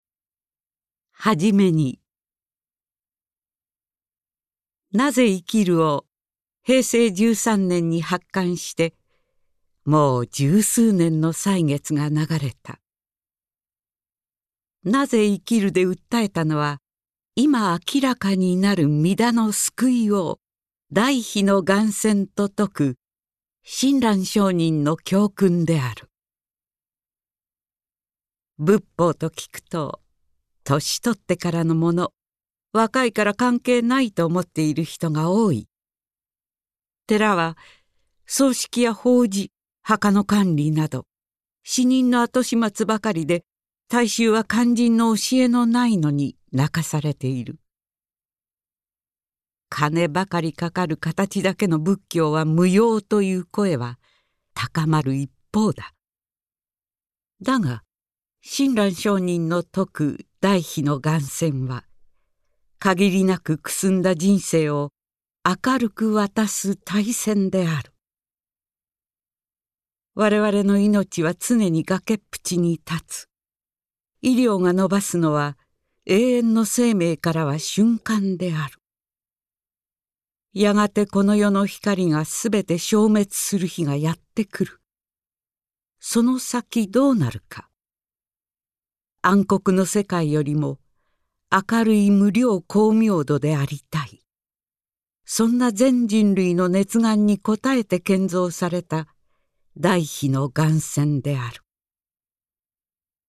「生・老・病・死の苦しみ渦巻く人生を、明るく楽しく渡す『大船』がある」と明言する親鸞聖人の言葉を、ベテラン声優の朗読でお聴きください。